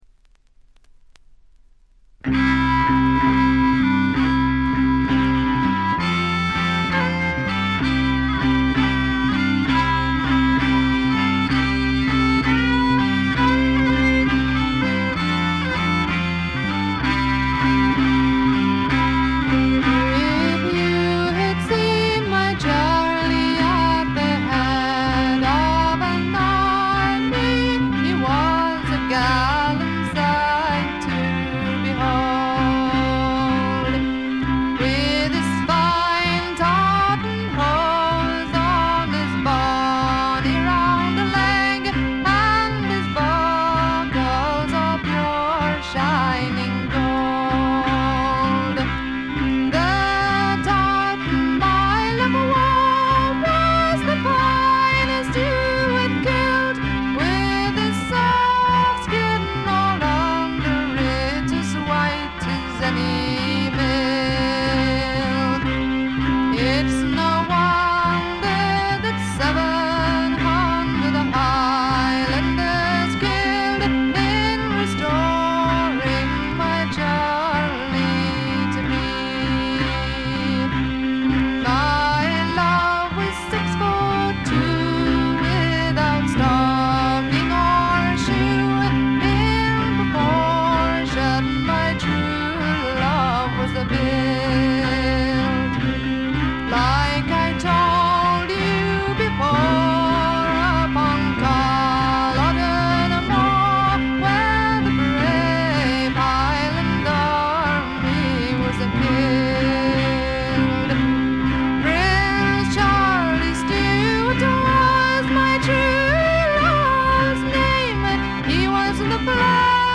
これ以外は静音部で軽微なチリプチ、バックグラウンドノイズ。
英国エレクトリック・フォーク最高峰の一枚。
試聴曲は現品からの取り込み音源です。